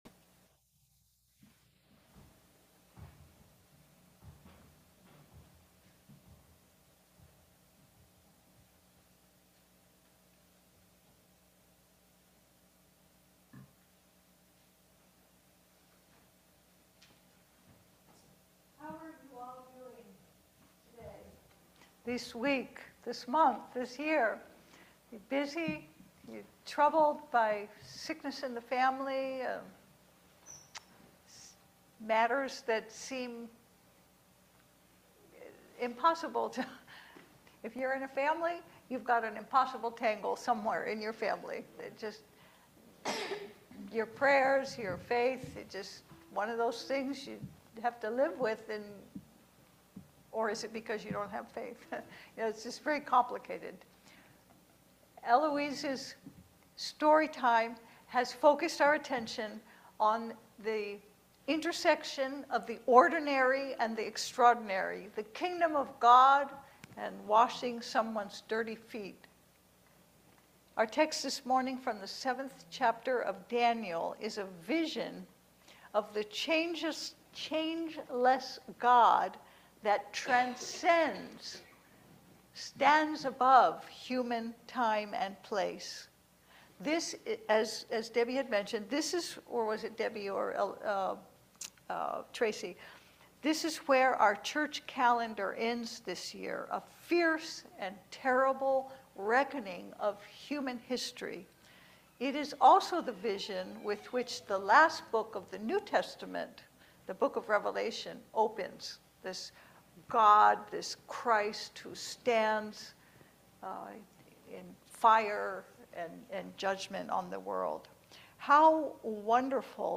Passage: Daniel 7:9-10, 13-14 Service Type: Sunday Service